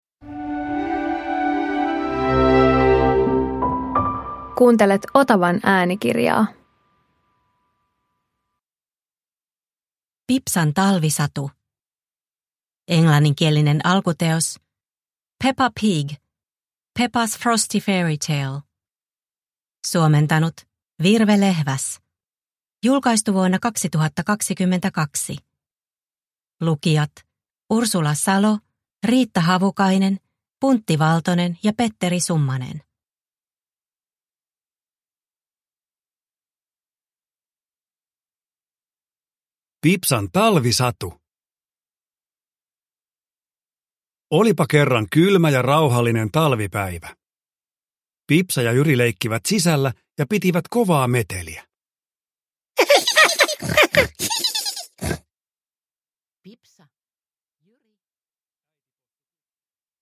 Pipsan talvisatu – Ljudbok – Laddas ner